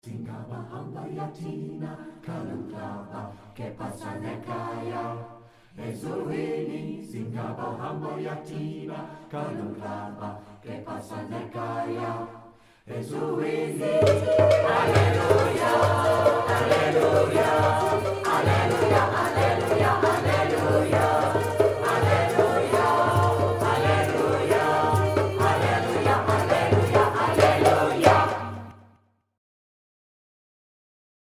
Trad. South African